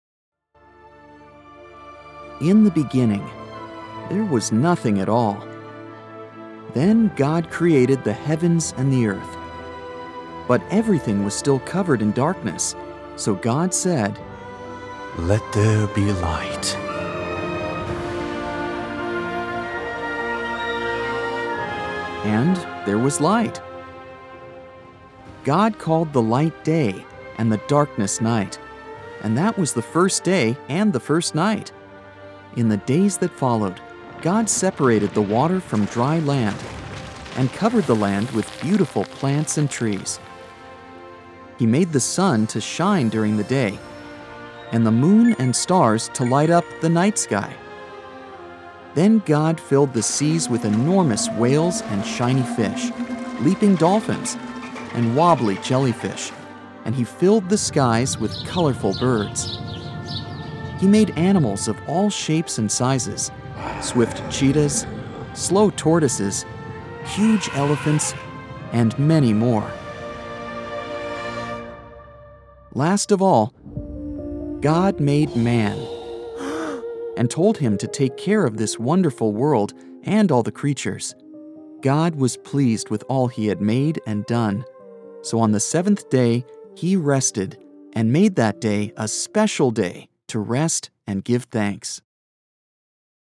Audiobook Demo – Religious